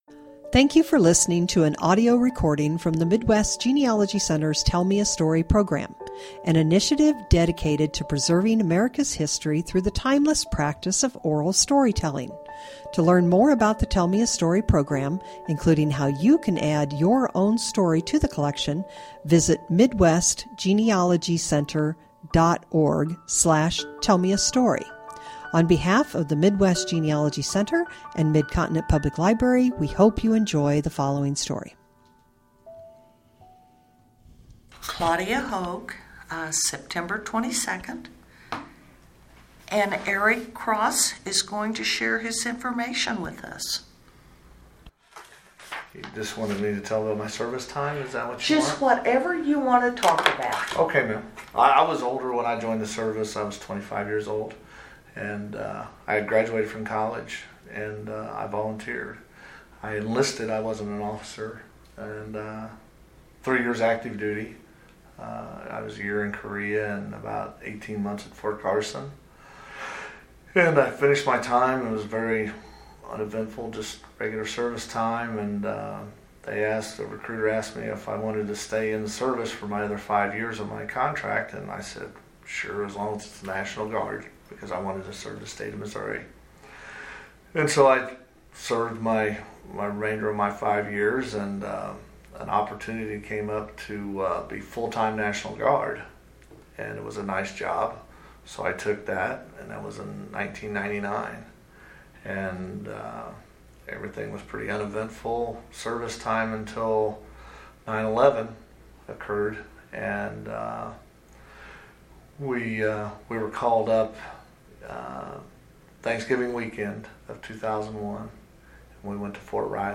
Oral History
interviewer
interviewee